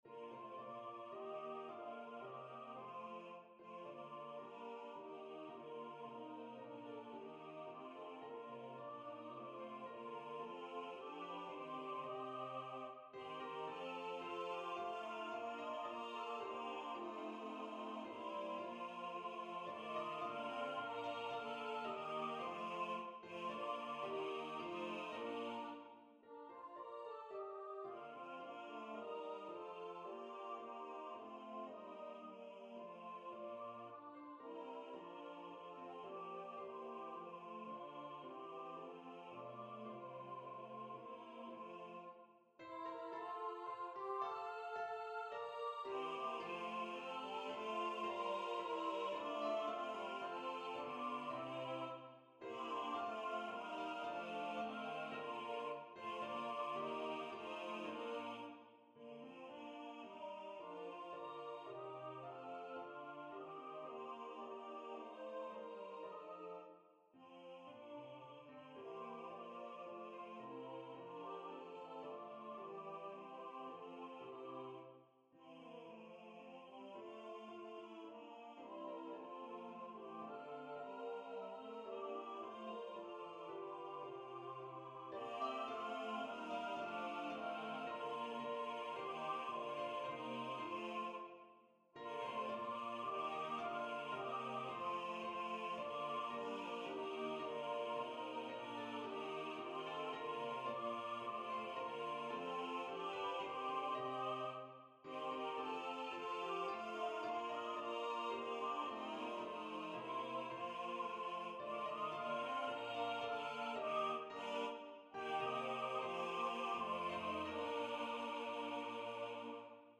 Voices: SATB Instrumentation: a cappella
NotePerformer 4 mp3 Download/Play Audio